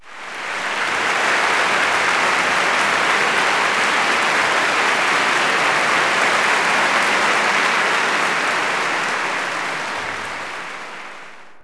clap_040.wav